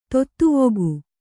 ♪ tottuvogu